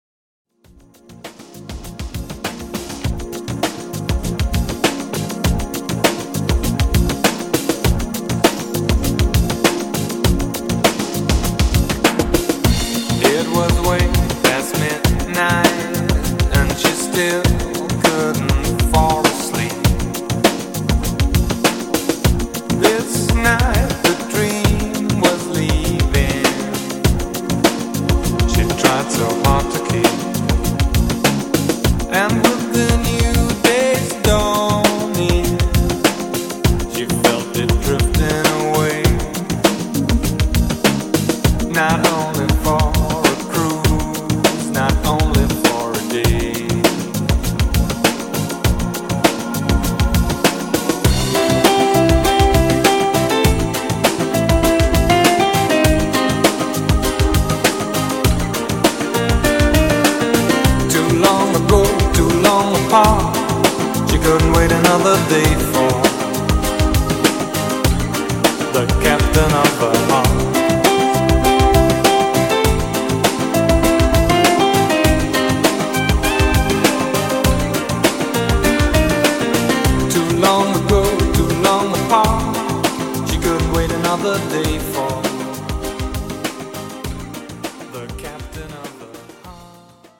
80s Extended)Date Added